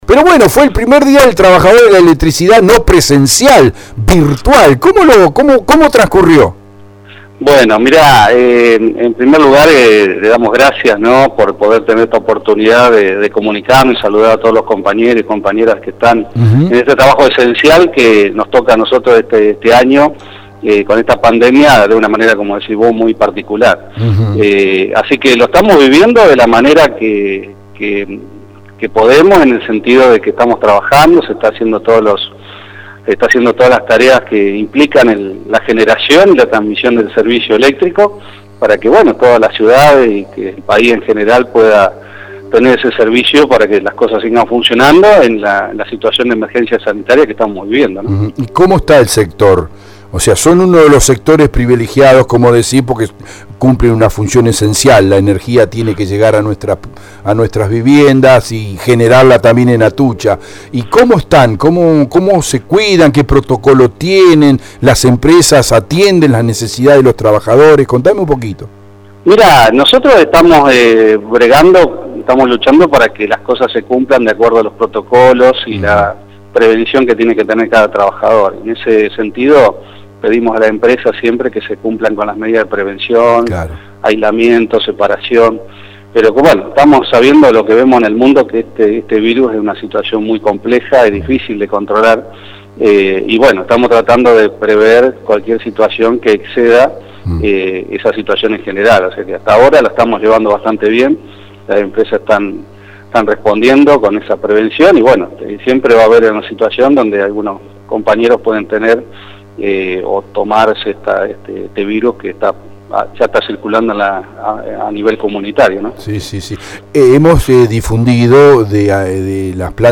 Antes de terminar la entrevista, expresó “quiero saludar a todos los compañeros de trabajadores y trabajadoras de la Central y de la Cooperativa de Electricidad en este día tan especial y como habíamos dicho siempre vamos a estar brindando el servicio nuestro para la comunidad de la zona”, y respecto a su pensar lo que está por venir, post pandemia,  manifestó “Yo creo que a veces el ser humano tiene que tocar ciertos límites para poder darse cuenta que las personas somos frágiles y que, tenemos un tiempo limitado en esta tierra y que debemos reconocer que no nos vamos a llevar nada de lo que tenemos”, terminó.